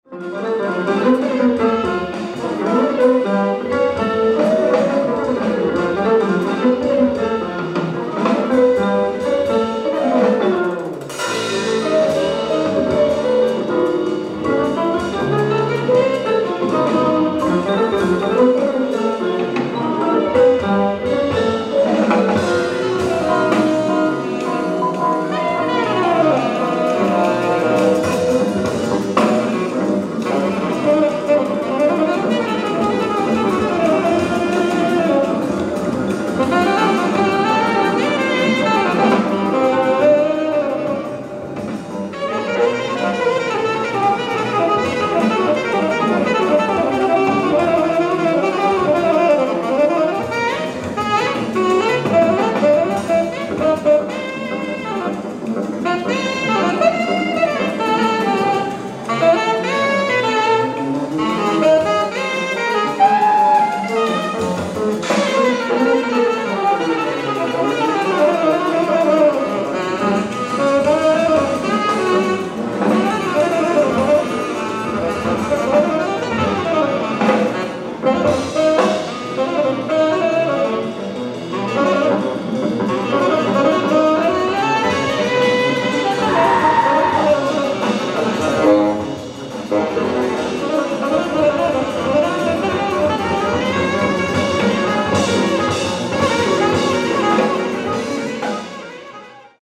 ライブ・アット・クラブ・レセダ、カリフォルニア 03/07/1982
※試聴用に実際より音質を落としています。